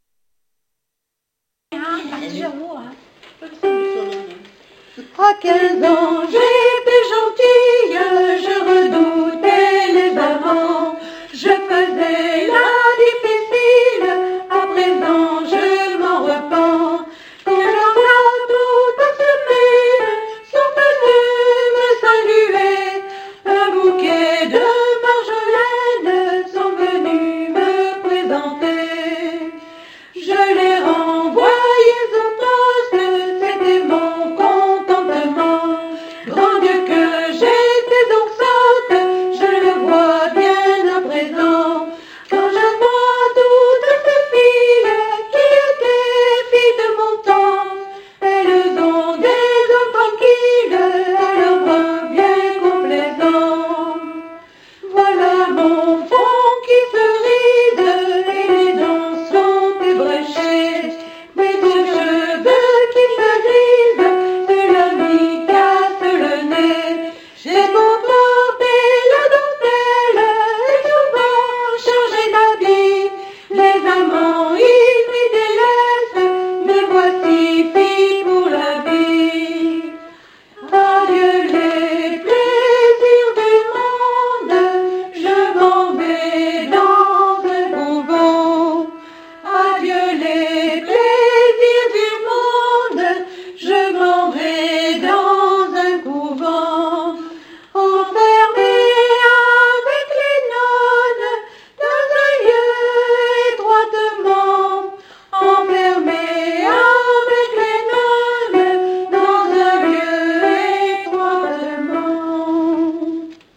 alti 1